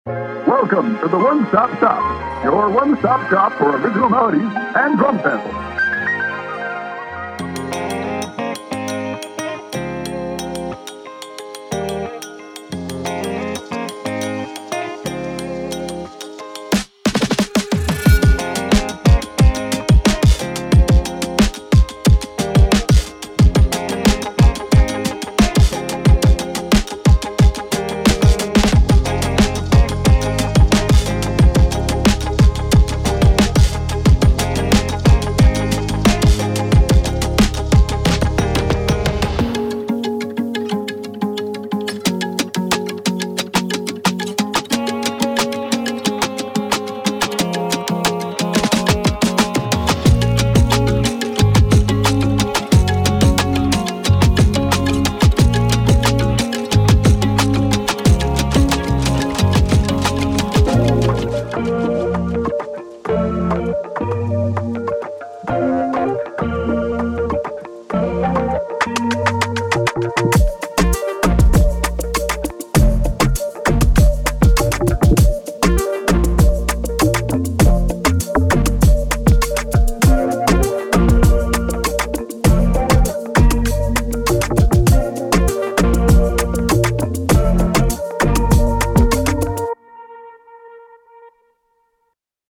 Pop
multi-instrumentalist